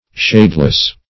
Shadeless \Shade"less\, a. Being without shade; not shaded.